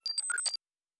pgs/Assets/Audio/Sci-Fi Sounds/Electric/Data Calculating 1_3.wav at master
Data Calculating 1_3.wav